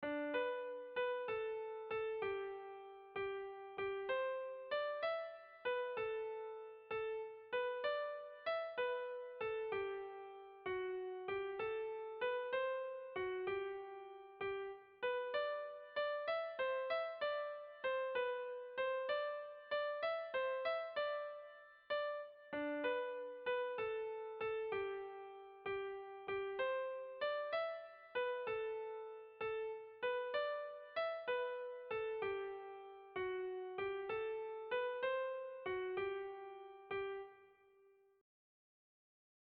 Sentimenduzkoa
ABD1D2B